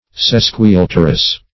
Sesquialterous \Ses`qui*al"ter*ous\, a.
sesquialterous.mp3